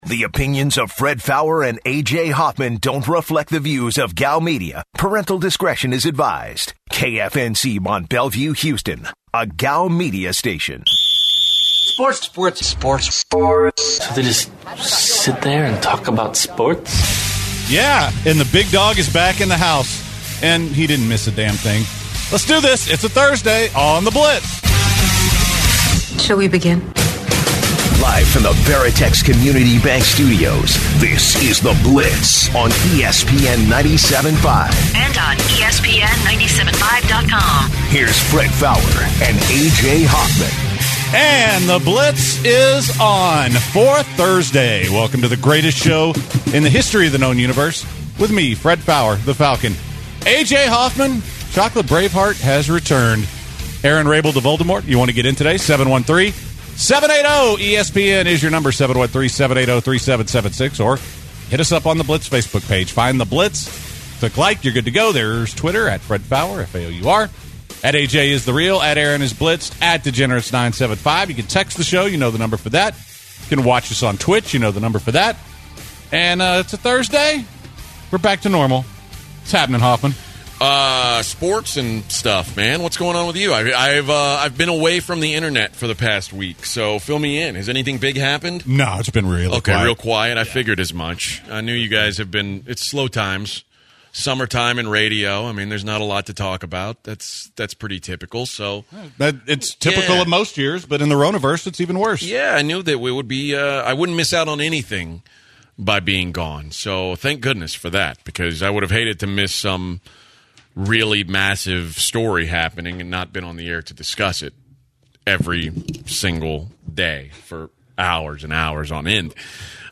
The guys kick things off today discussing the ongoing protests across the nation in response to George Floyd's murder. Then they take a couple of calls from police officers to hear their opinion on the situation.